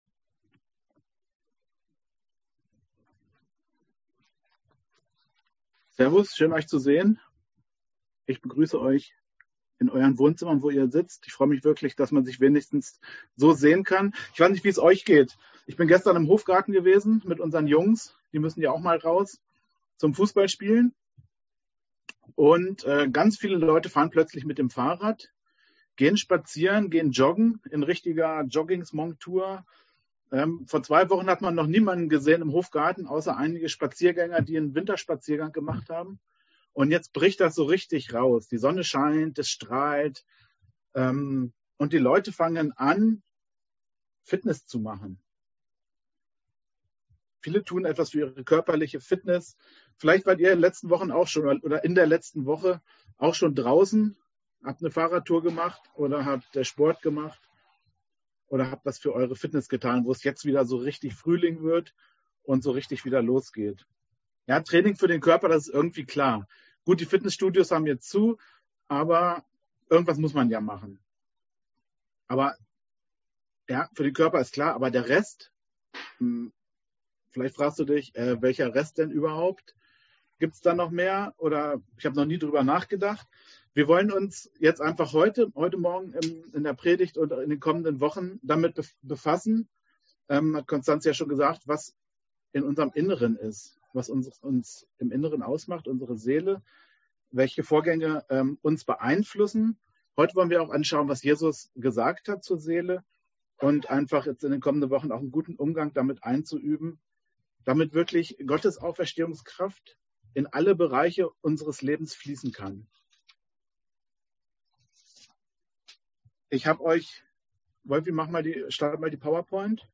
Predigt vom 25. April 2021 über Zoom